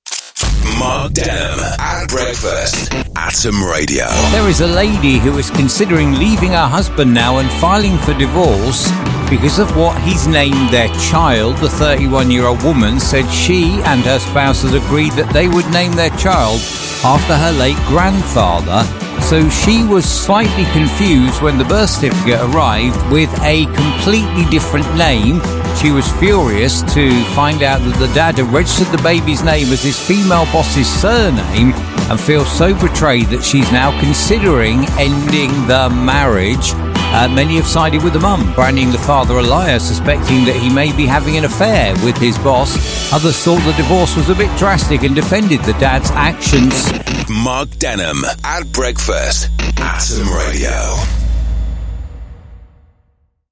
Atom Radio Breakfast Show